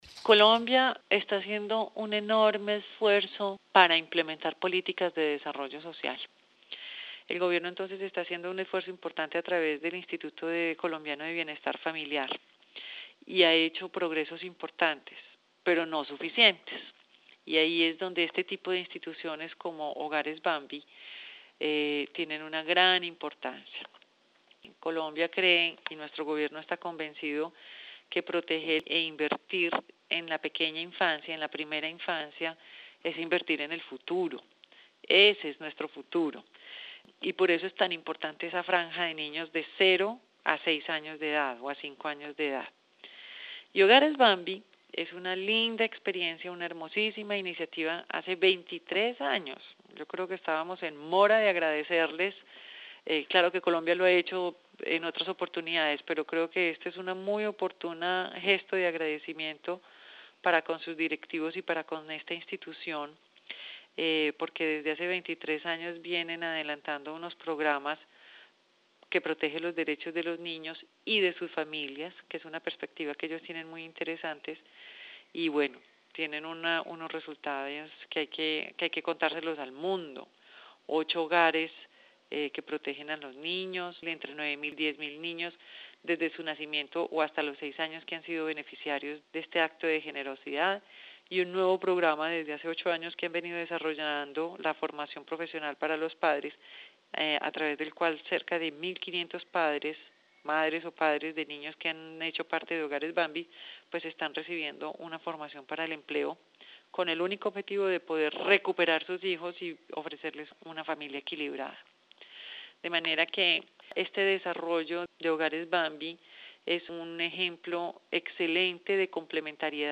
Claudia Jiménez, embajadora de Colombia, en entrevista con swissinfo